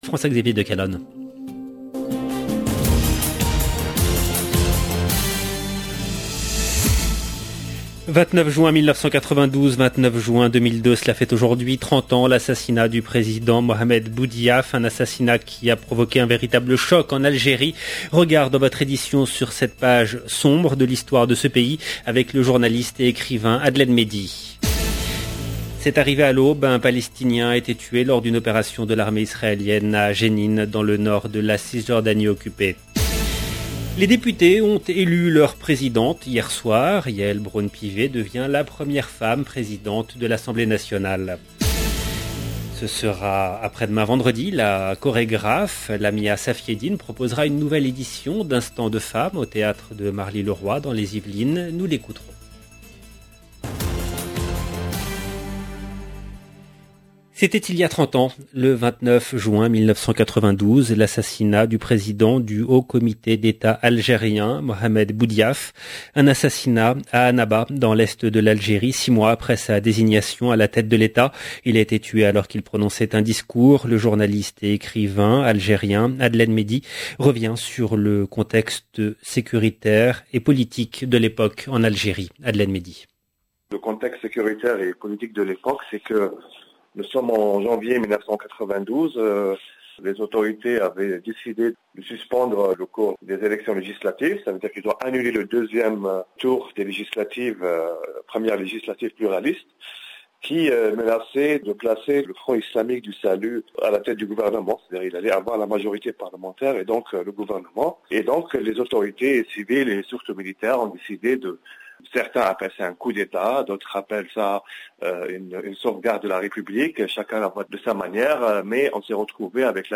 Journal